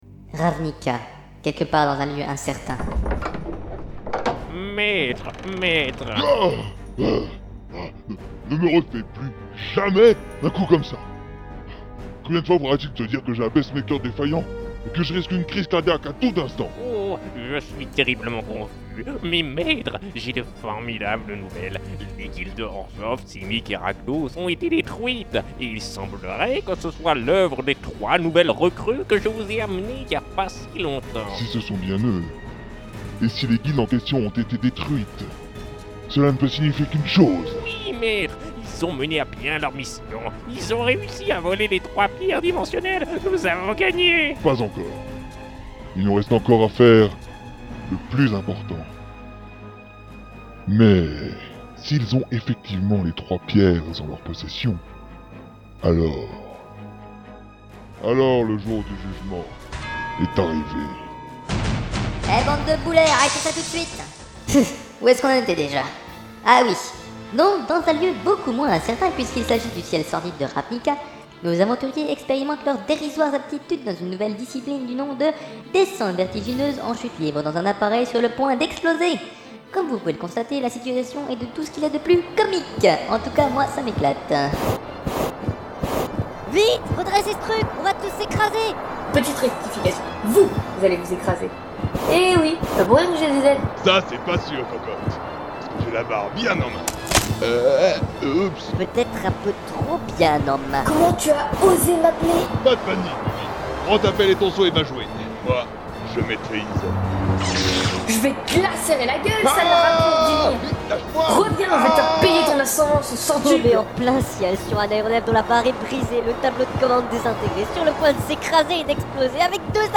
Page de la saga mp3 des Chroniques de Ravnica